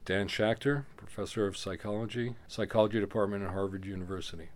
Yesterday I had the pleasure of speaking with Dr. Dan Schacter, in William James Hall at Harvard University (Cambridge, MA)!
schacter_introducehimself.mp3